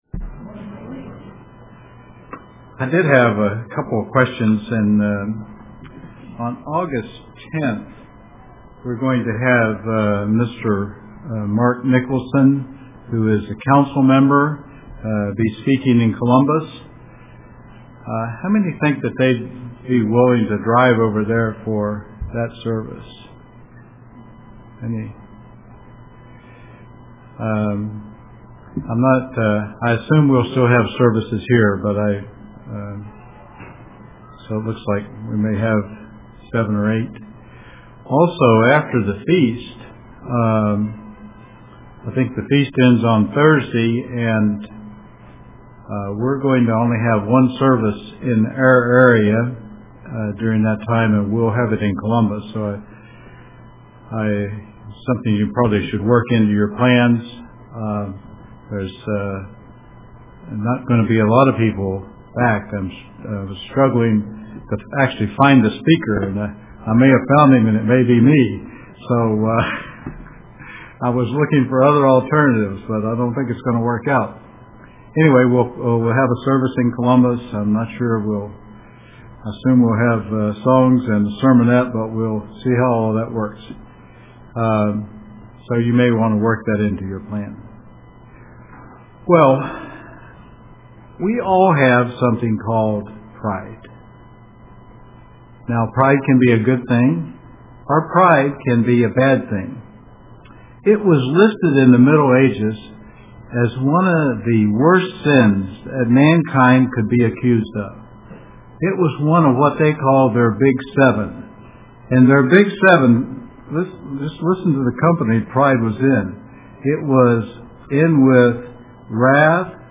Pride of Looks, Intellect, and Power UCG Sermon Studying the bible?